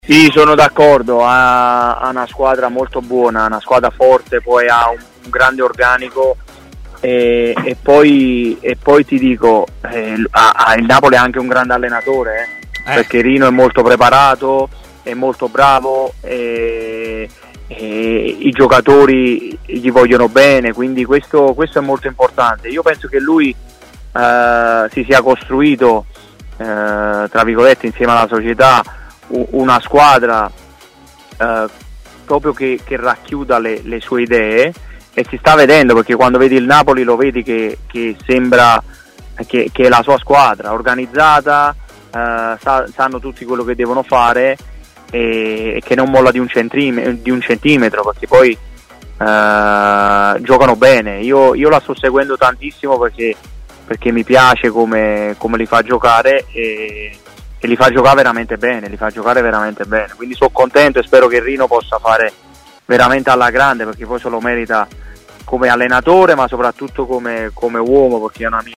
Dagli Stati Uniti l'ex centrocampista Antonio Nocerino si è collegato con Stadio Aperto, trasmissione di TMW Radio.